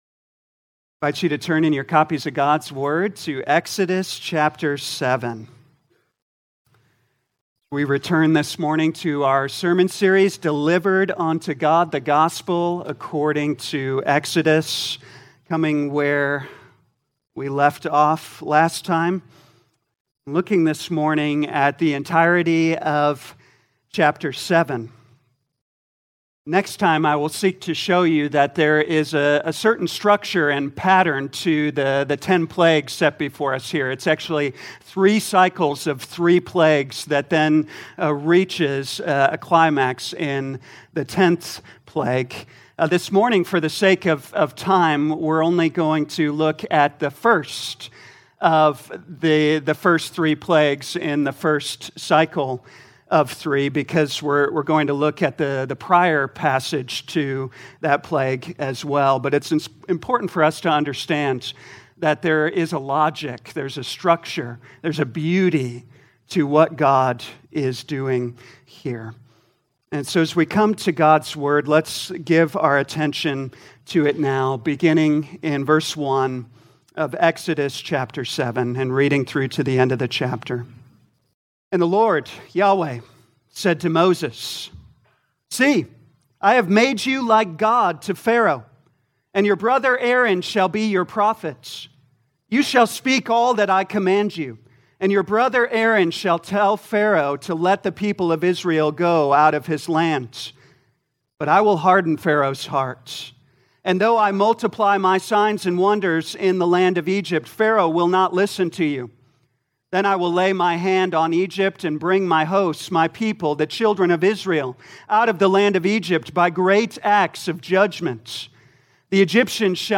2024 Exodus Morning Service Download